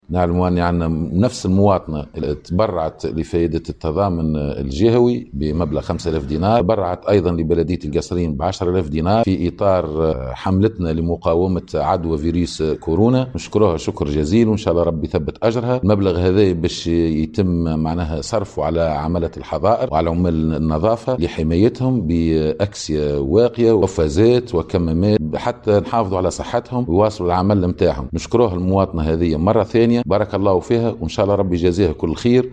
تصريح رئيس بلدية القصرين المدينة محسن مدايني